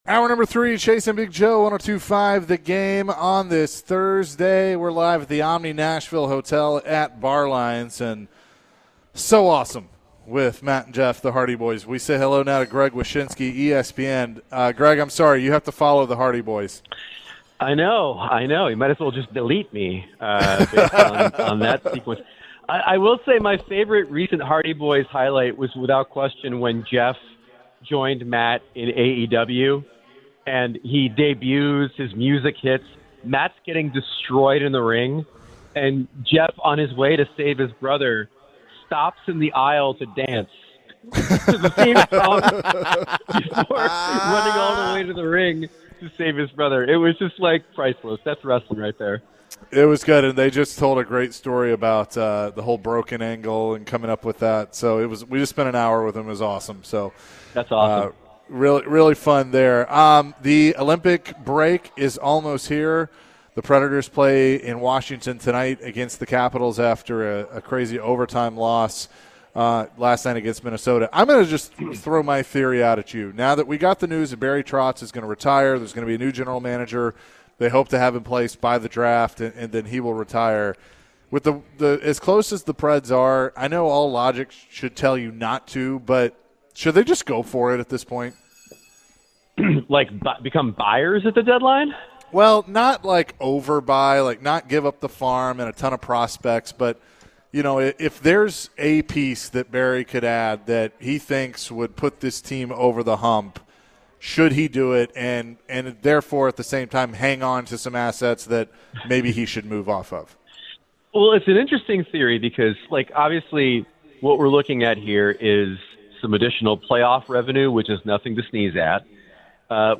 ESPN NHL Senior Writer Greg Wyshynski joined the show and shared his thoughts on Barry Trotz's stepping down, the next GM search, Juuse Saros's lack of production, and much more. Plus, Greg shared what he thinks will happen at the Winter Olympics.